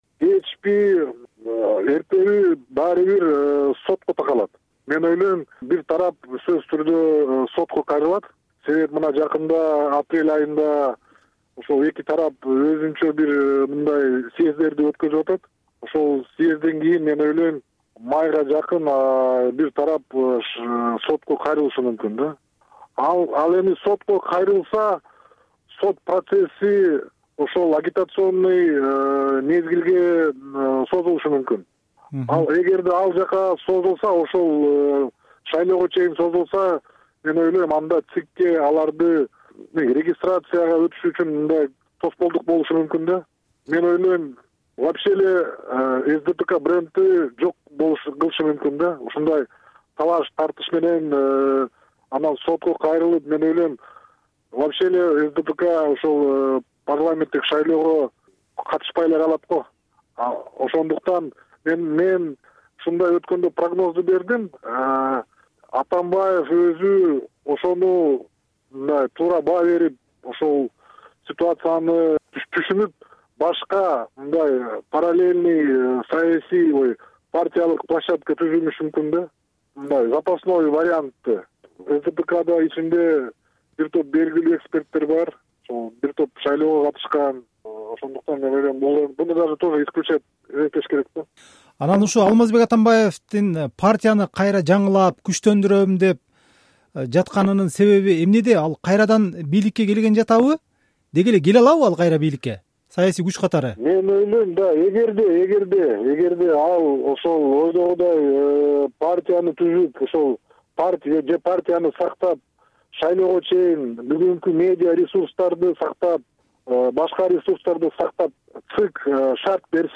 Кыргызстандагы Социал-демократтар партиясында ажырым болуп көрбөгөндөй күчөдү. Алар жакында эки башка курултай өткөргөнү жатышат. КСДПдагы таасир жана партия талаш тууралуу Жогорку Кеңештин экс-депутаты, саясий талдоочу Алишер Мамасалиев "Азаттыктын" суроолоруна жооп берди.